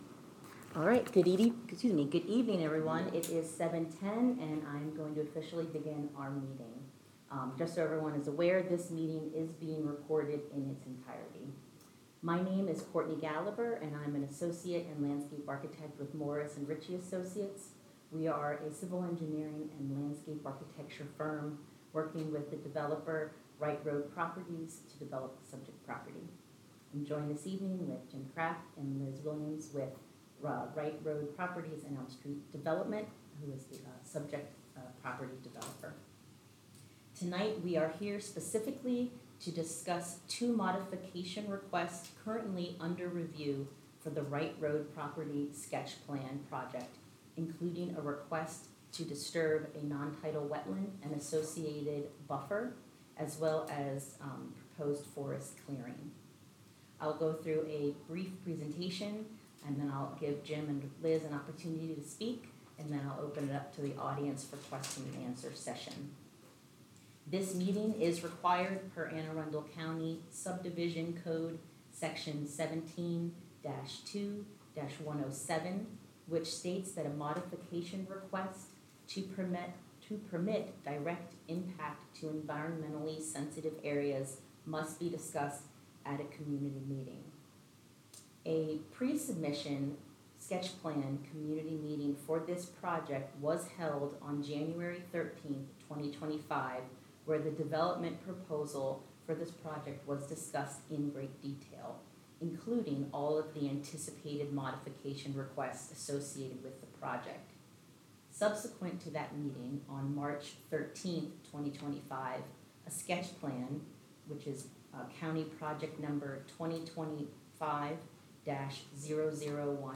Wright Road – Modification Requests Community Meeting